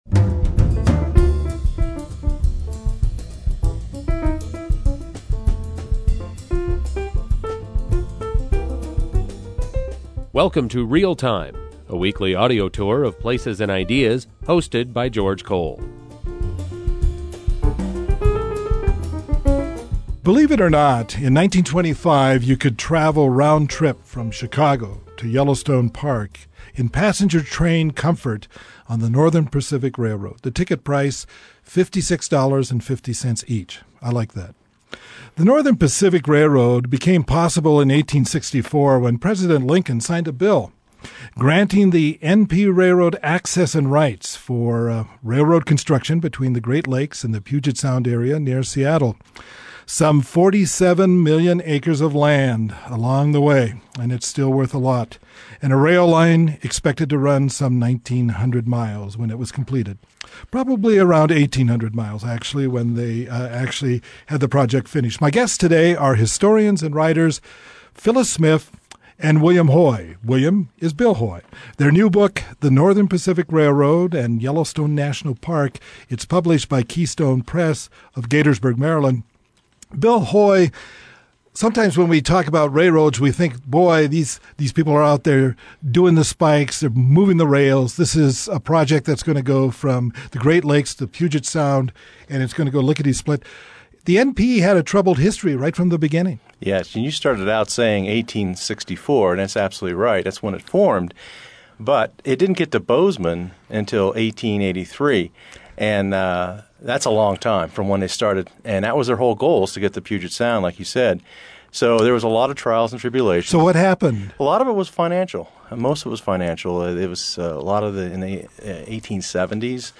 Interview with the Authors